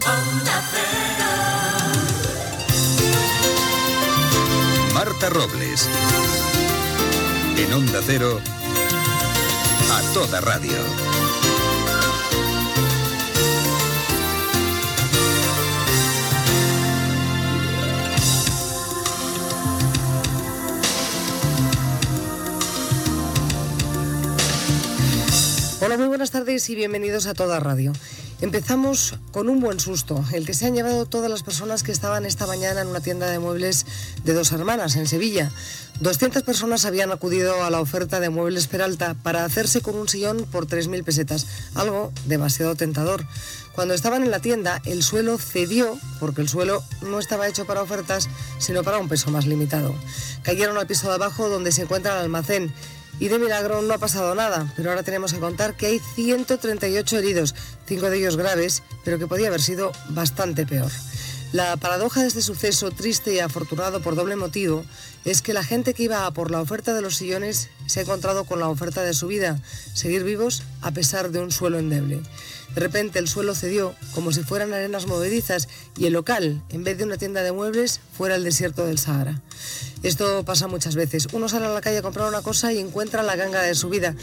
Indiactiu de la ràdio, careta, inici del programa i notícia d'un accident a una tenda de mobles de Sevilla.
Entreteniment